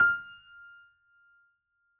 piano_077.wav